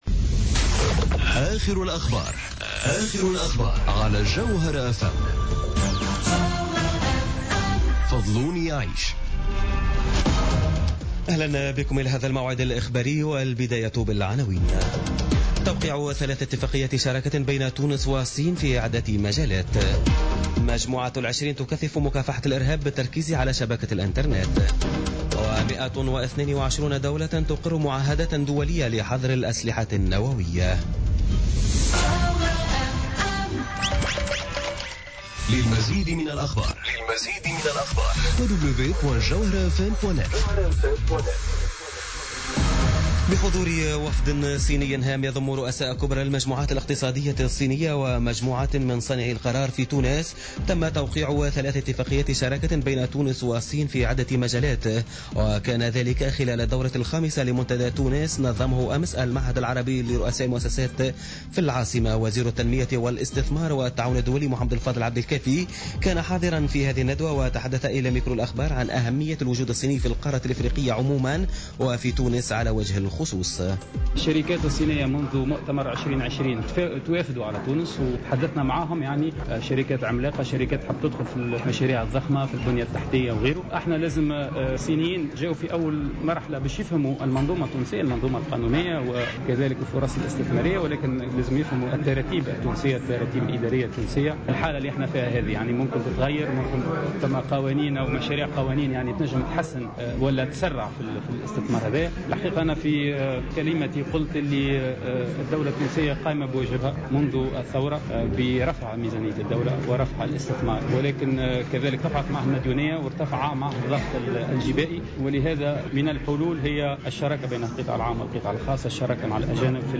نشرة أخبار منتصف الليل ليوم السبت 08 جويلية 2017